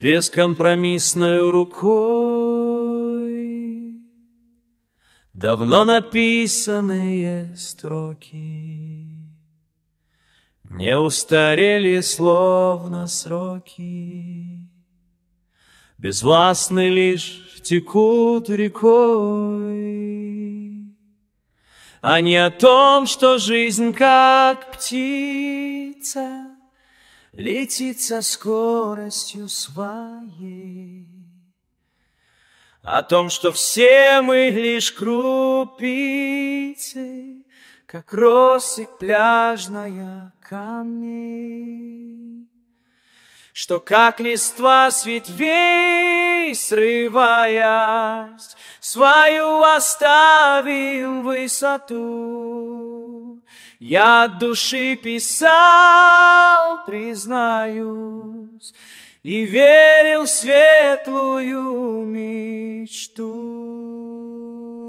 Текст - авторський, виконання - штучний інтелект
ТИП: Пісня
ВИД ТВОРУ: Авторська пісня